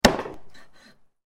Meat-chopper-knife-sound-effect.mp3